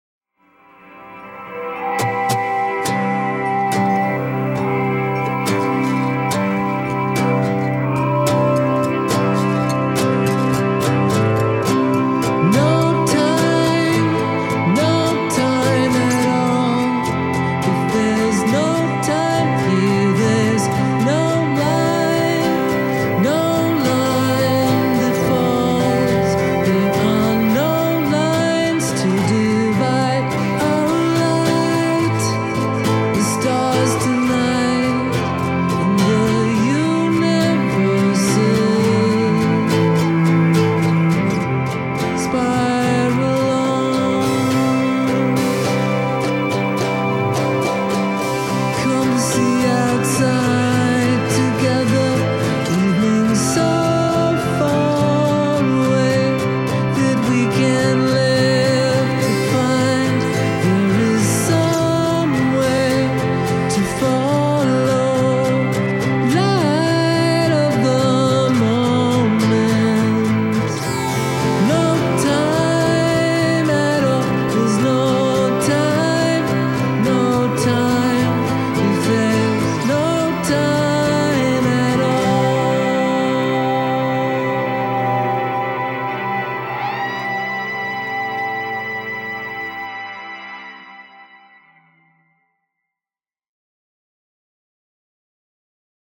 It’s like a meditation in motion!!!